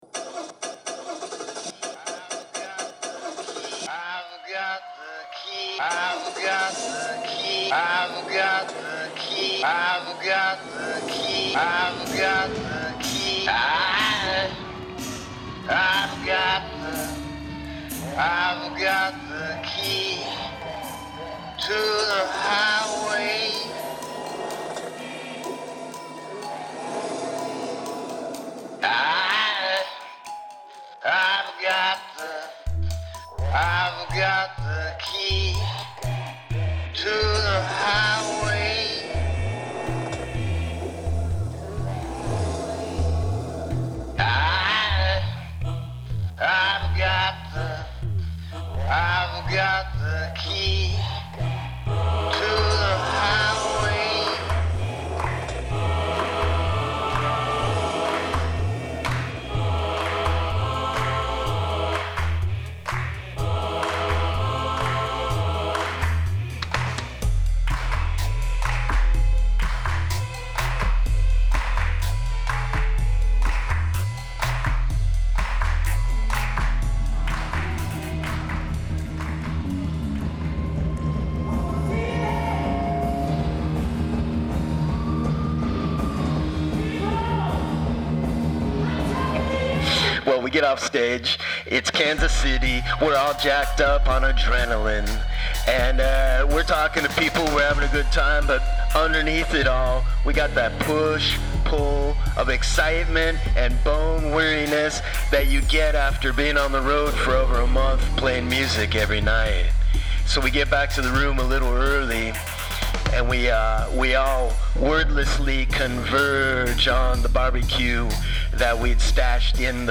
Radio BirdMouth is a loose-form audio roadtrip woven together from fragmentary spoken word narratives and sound manipulations.